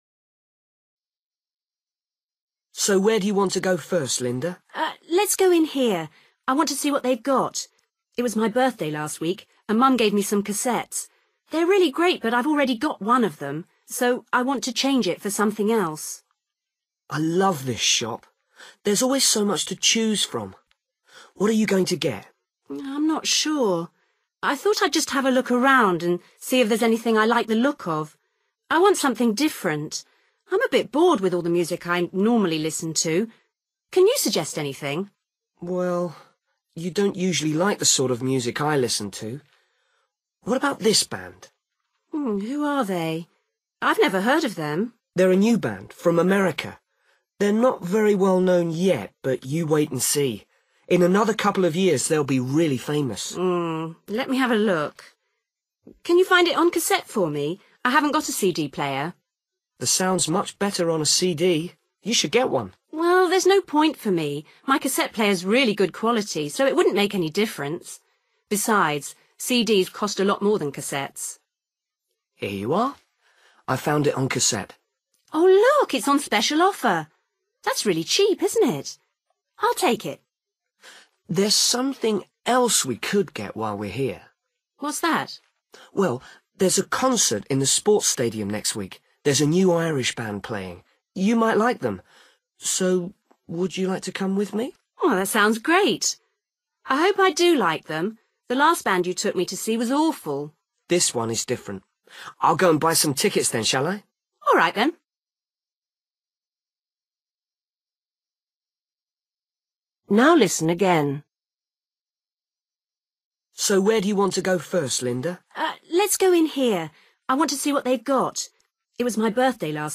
You will hear a conversation between a boy
in a music shop.